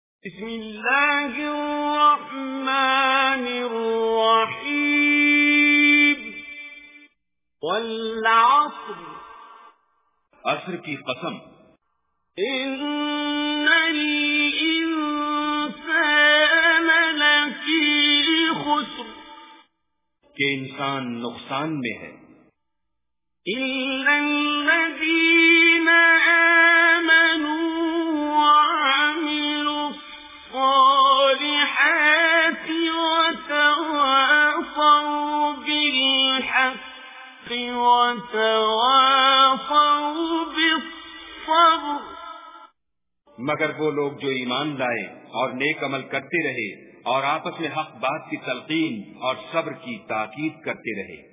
Surah Asr Recitation with Urdu Translation
Listen online and download mp3 tilawat / recitation of Surah Asr in the voice of Qari Abdul Basit As Samad.
surah-asr.mp3